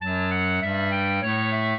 clarinet
minuet14-6.wav